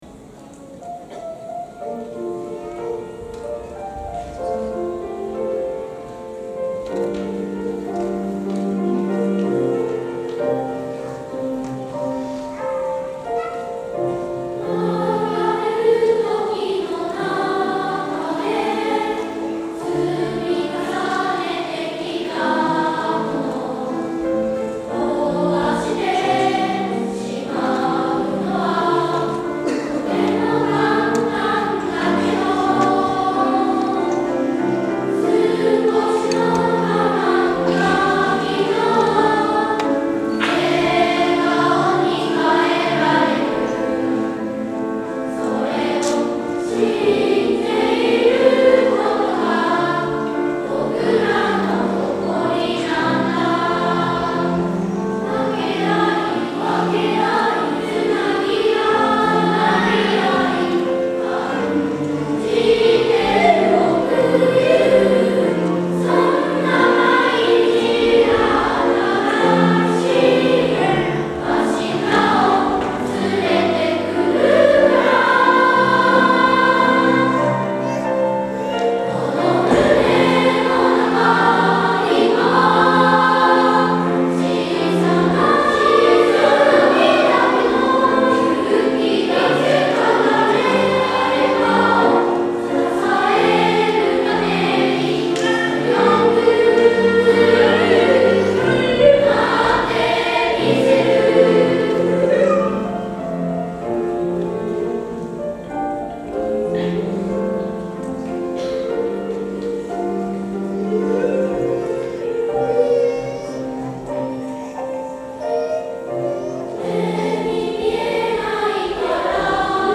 5・6年生（高学年チーム）　2部合唱「
今日は、一人ひとりの思いを存分にハーモニーにのせ、会場中のみんなに届けました。